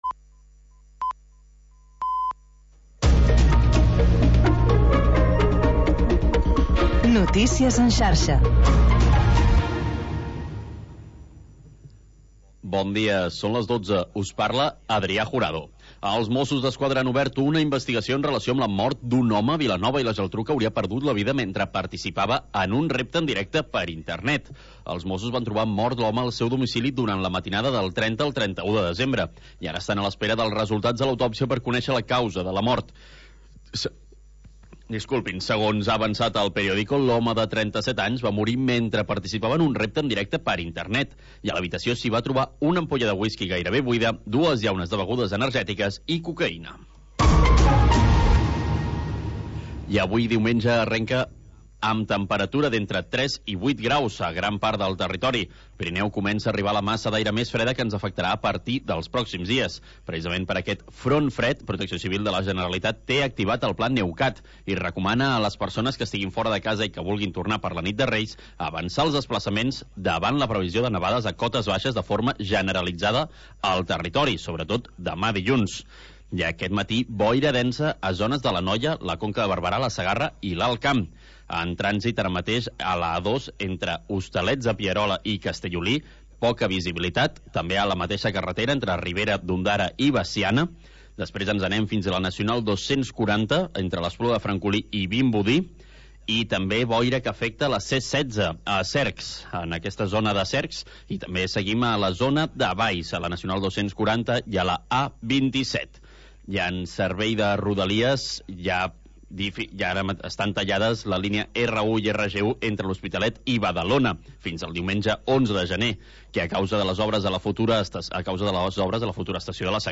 Música nostàlgica dels anys 50, 60 i 70